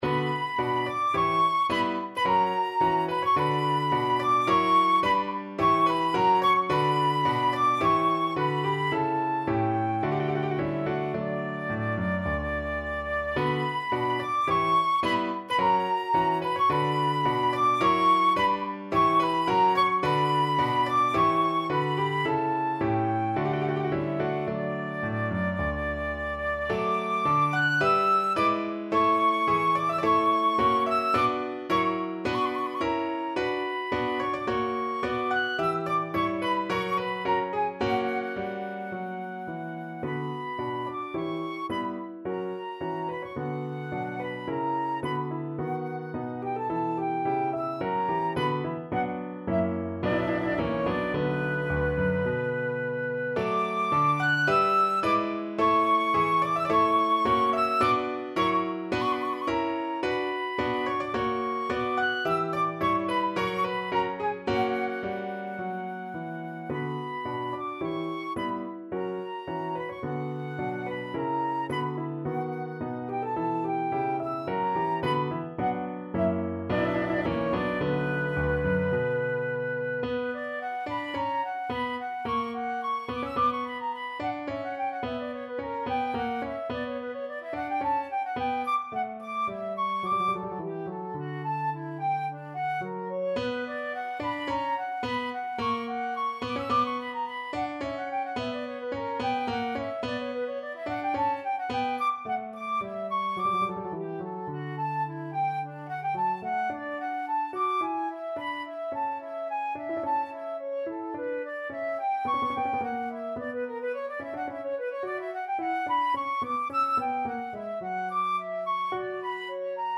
B minor (Sounding Pitch) (View more B minor Music for Flute )
~ = 54 Moderato
Flute  (View more Intermediate Flute Music)
Classical (View more Classical Flute Music)